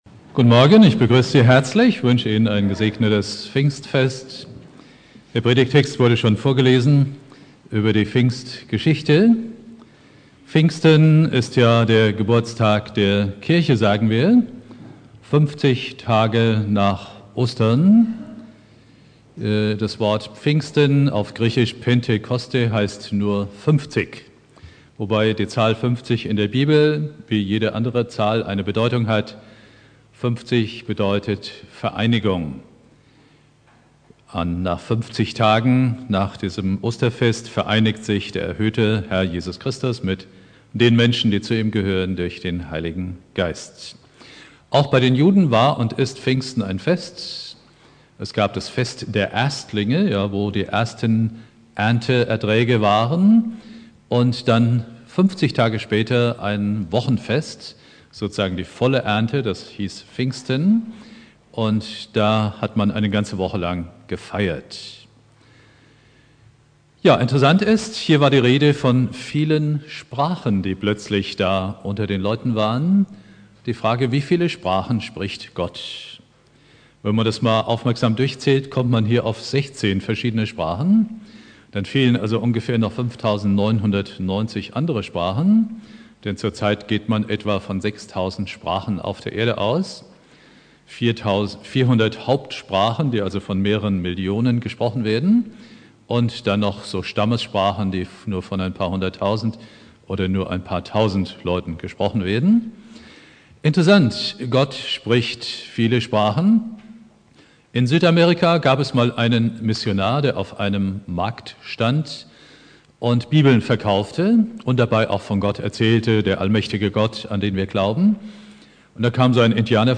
Predigt
Pfingstsonntag Prediger